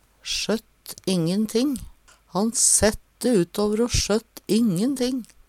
sjøtt ingen ting - Numedalsmål (en-US)
sjøtt ingen ting ikkje redd for noko Eksempel på bruk Han sette utåver o sjøtt ingen ting. Sjå òg gapute (Veggli) Høyr på uttala Ordklasse: Uttrykk Kategori: Uttrykk Attende til søk